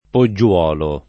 poggiuolo [ po JJU0 lo ]